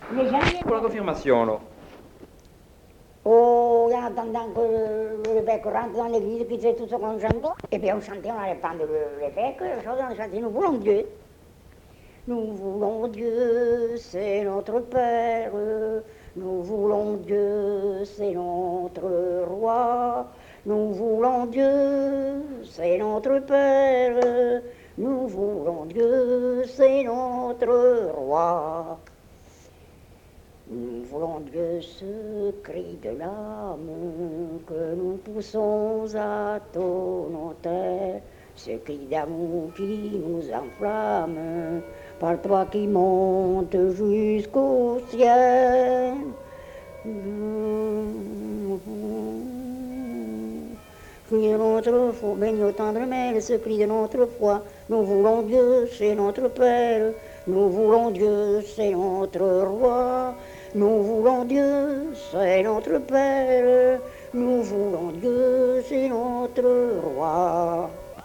Lieu : Mas-Cabardès
Genre : chant
Effectif : 1
Type de voix : voix de femme
Production du son : chanté
Contextualisation de l'item : pour la confirmation.